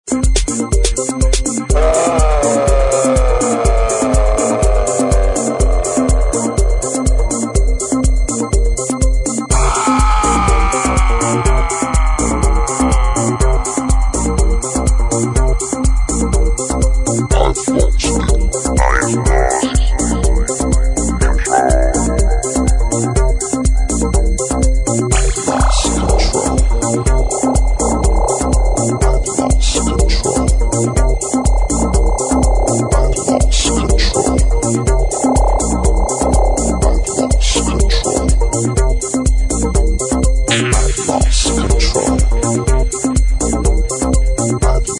Disco House Acid